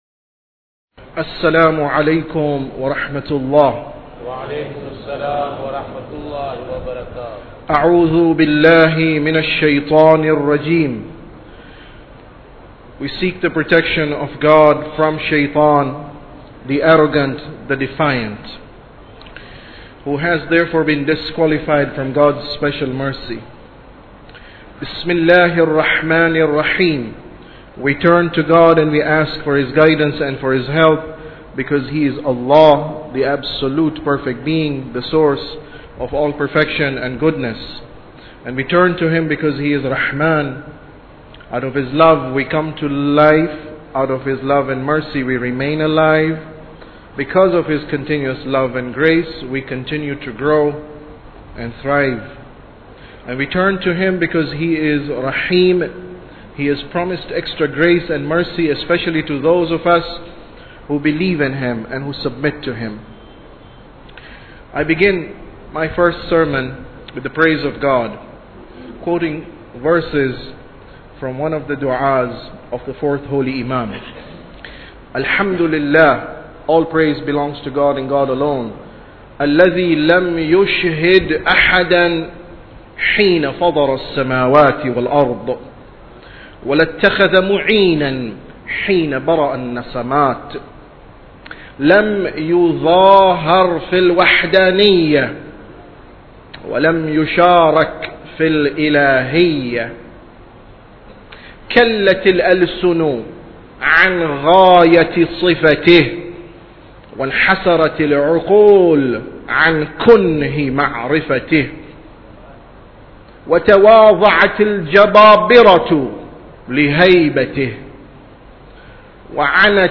Sermon About Tawheed 9